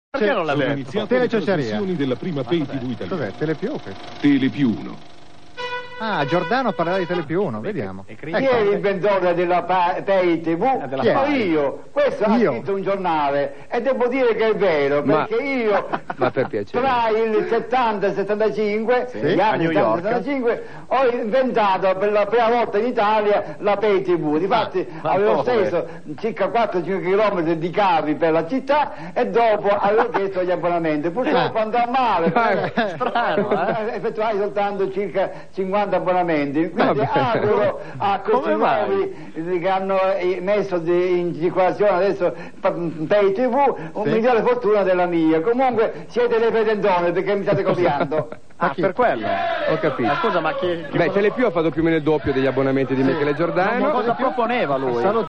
Ma ecco alcuni mp3 tratti da "Mai dire TV" della Gialappa's Band: